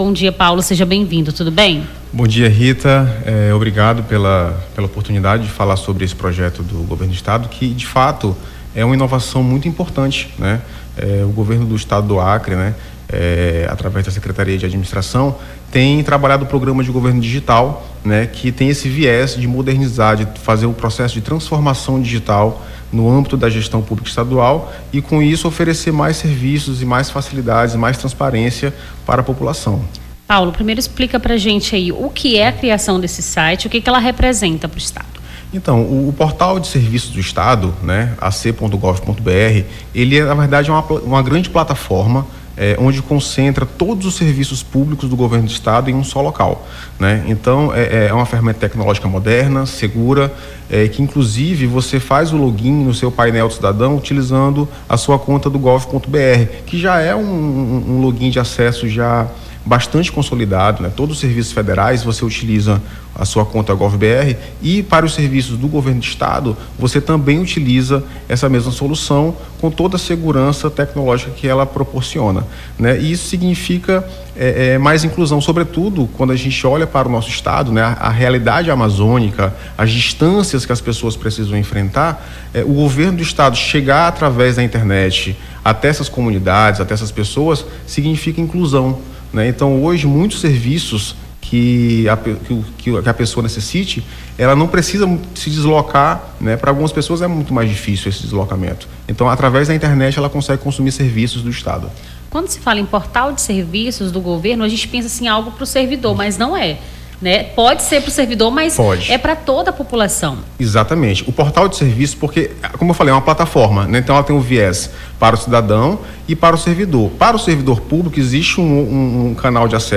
Nome do Artista - CENSURA - ENTREVISTA (PORTAL DE SERVIÇOS DO GOVERNO) 03-04-25.mp3